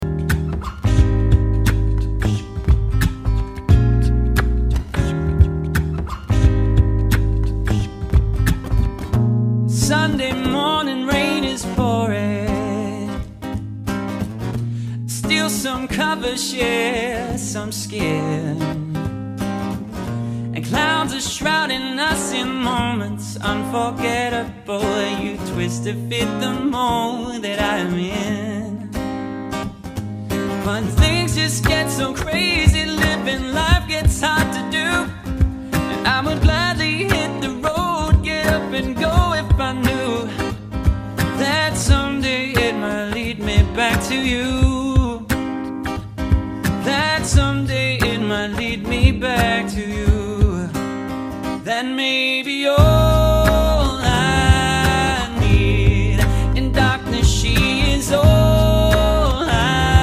Vocals | Guitar | Looping | DJ